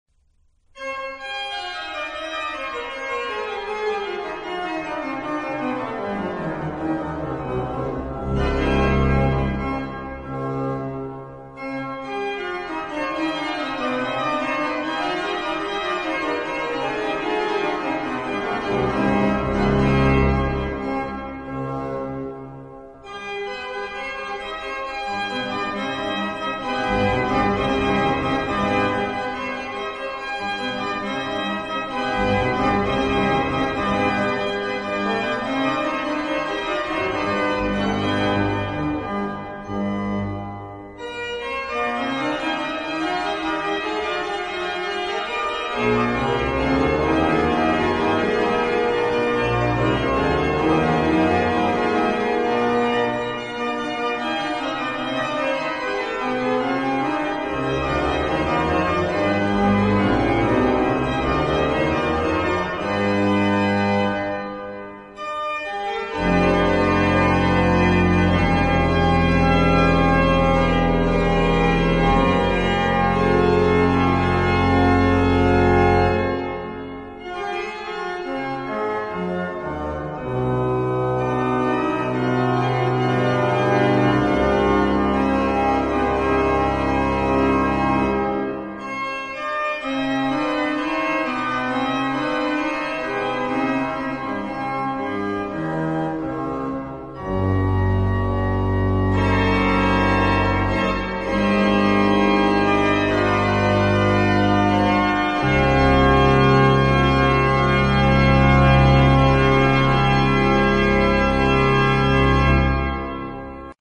Organ
on the historic Hinsz organ (1738)
in the Broederkerk, Kampen, Netherlands
lubeck_praeambulum_organ.mp3